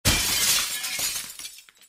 glass_crash.ogg